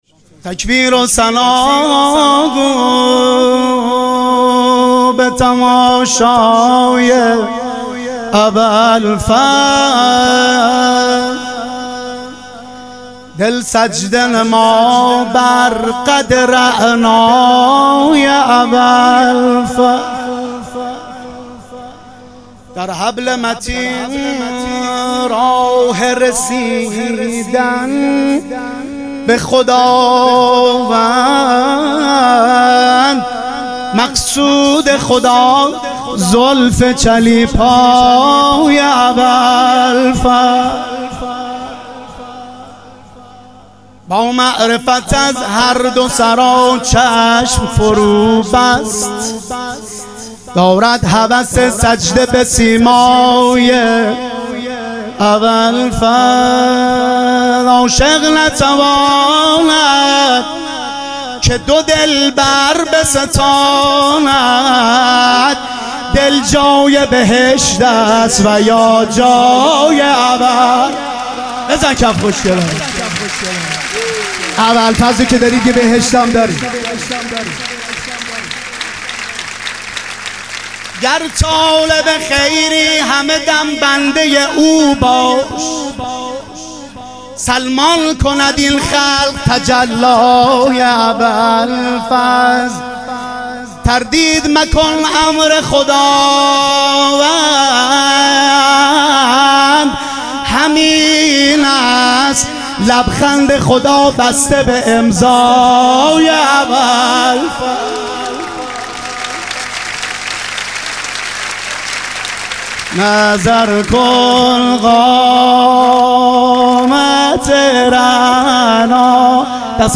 ظهور وجود مقدس حضرت عباس علیه السلام - مدح و رجز